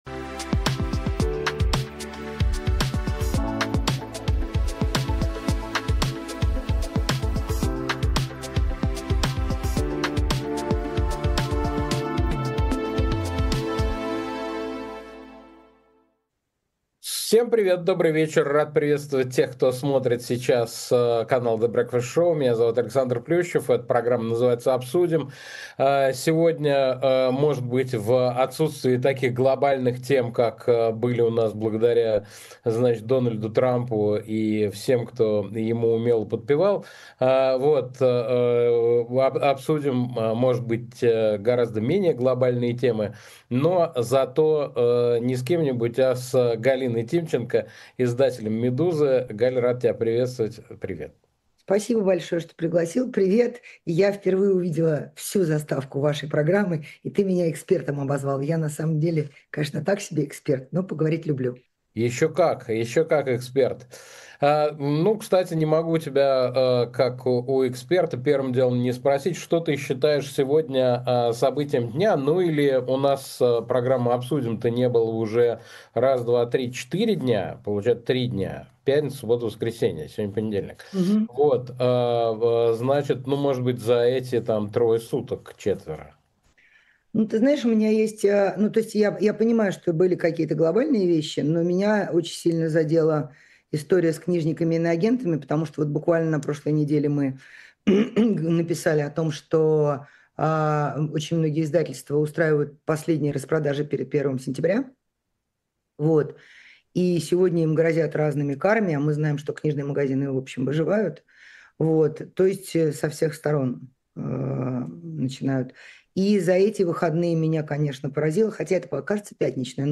Эфир ведёт Александр Плющев
Гость этого выпуска — издатель «Медузы» Галина Тимченко. Обсудим с ней, в чём смысл перестановок в российском руководстве, чем Соловьёв напугал Сергея Маркова и что натворил Вуди Аллен.